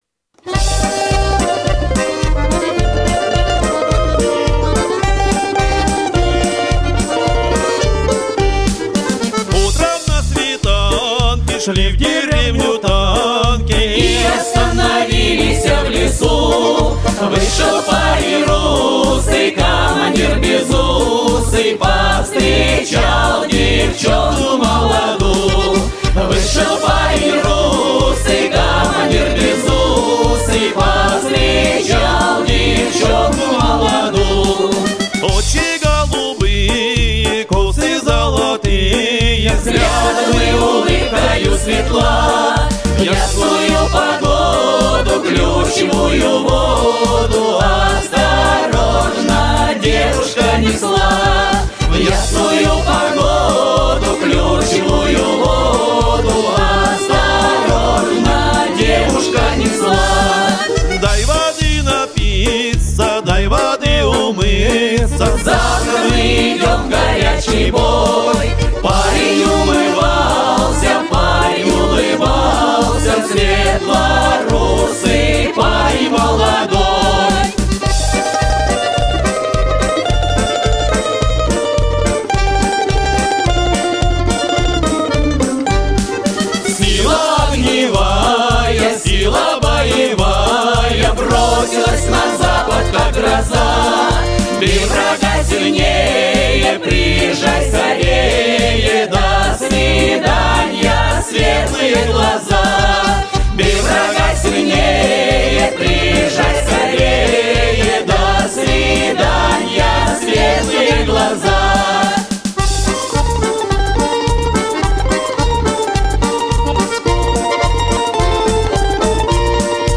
Коллектив почти год трудился в студии звукозаписи